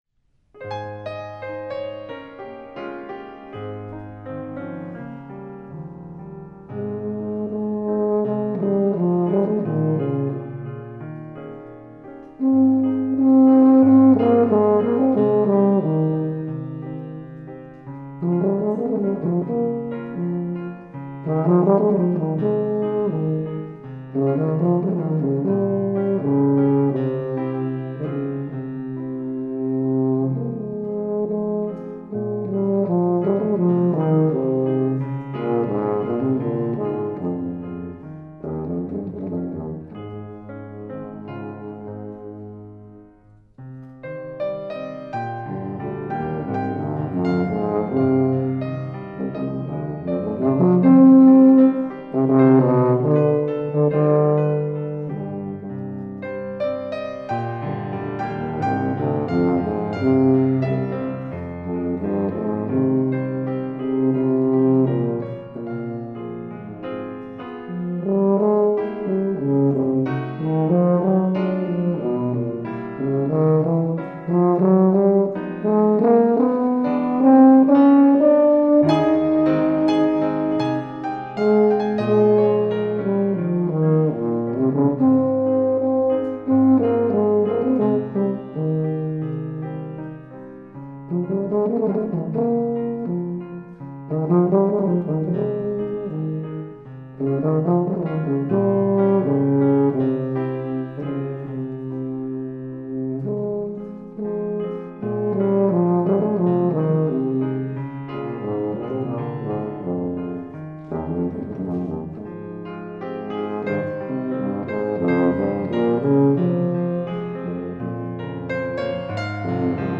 Faculty Recital 4-5-2012
tuba
piano